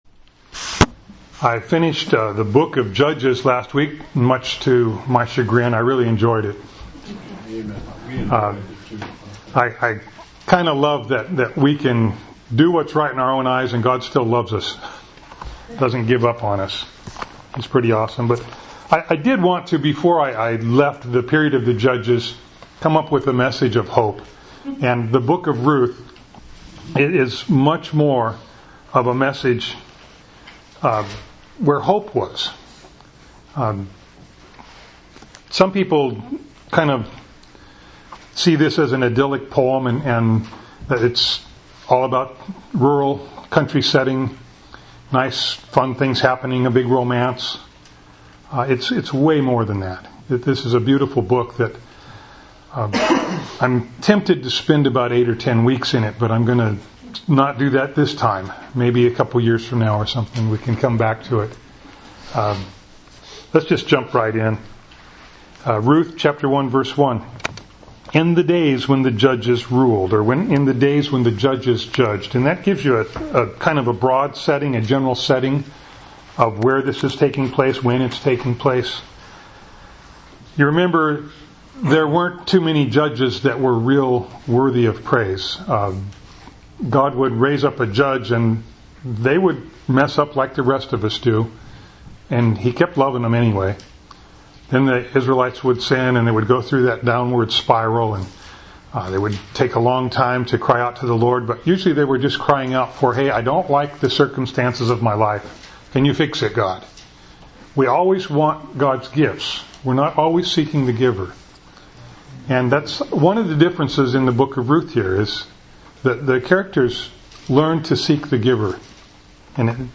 Bible Text: Ruth | Preacher